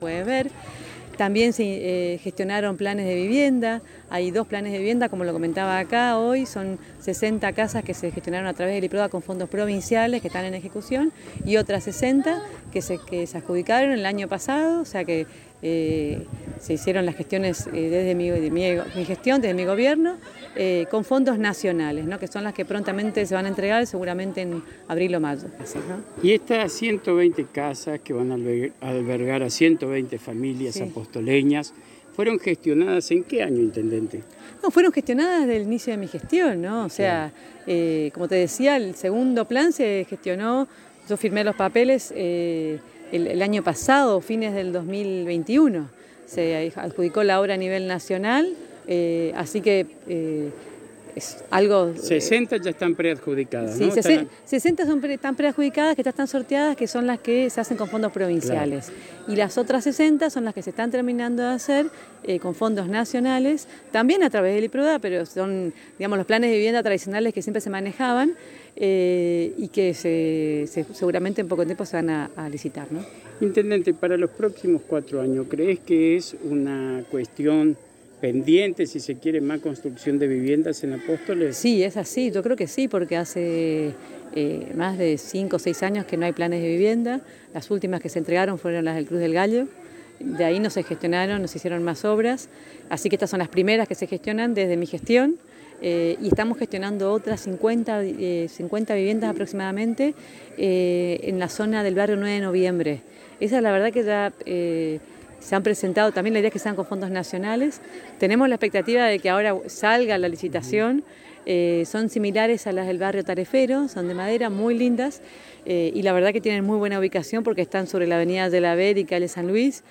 La Intendente de Apóstoles María Eugenia Safrán en diálogo exclusivo con la ANG y al ser consultada sobre las 120 viviendas que se están construyendo en el Municipio en qué momento fueron gestionadas respondió enfáticamente que fueron gestionadas en el principio de su gestión Sesenta con fondos provinciales y sesenta con fondos nacionales a principio del 2021. Además, resaltó que durante el año pasado se dieron 111 soluciones habitacionales en distintos barrios de Apóstoles y próximamente en el barrio Czesny se entregarán veinte viviendas más.